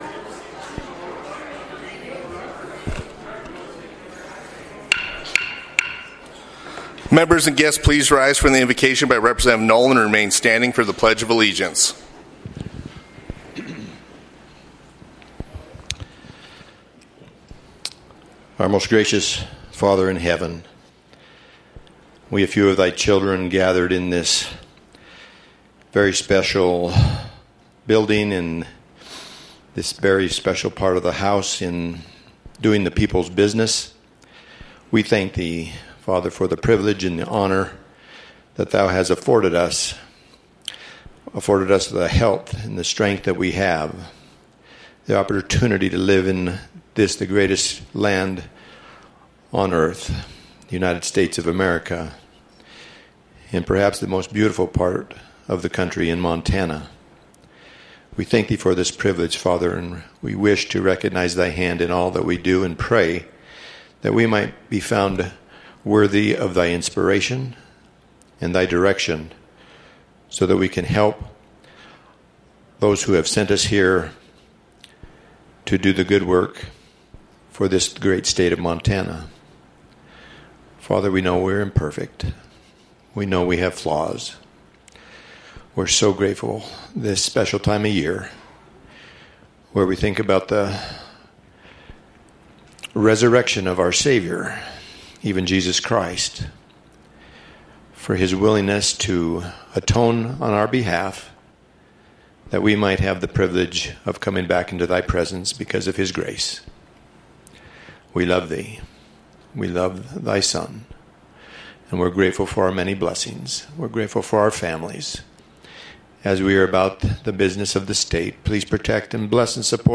House Floor Session